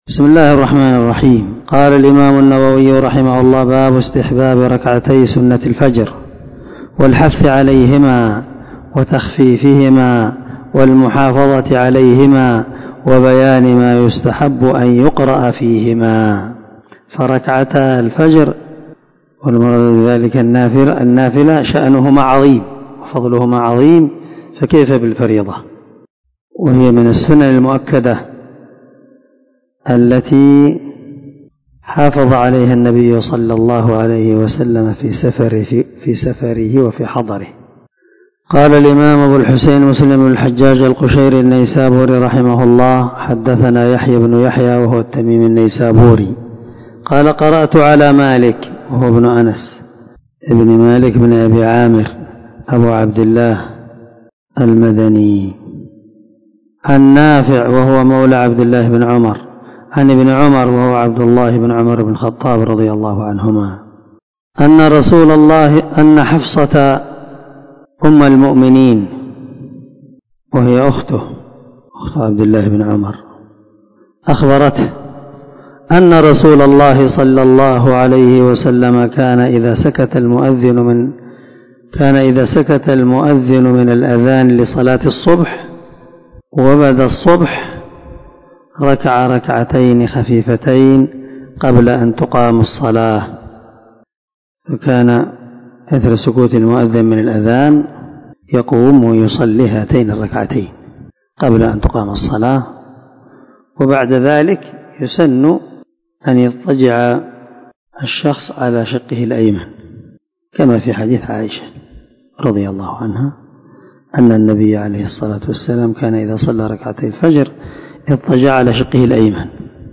449الدرس 17 من شرح كتاب صلاة المسافر وقصرها حديث رقم ( 723 – 725 ) من صحيح مسلم